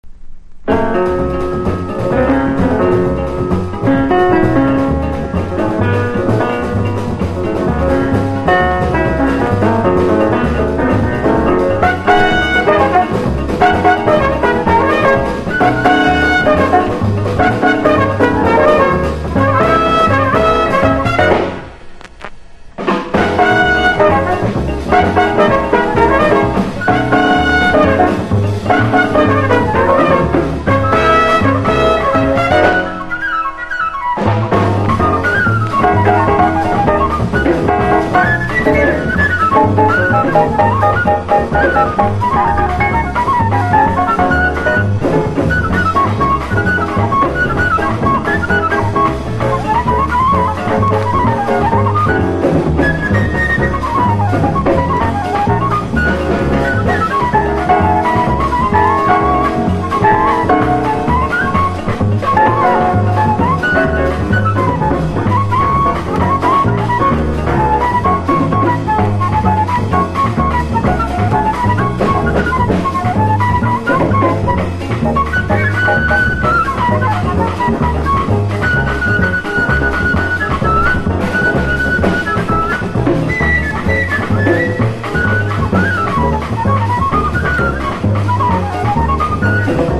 疾走感のあるジャズボッサ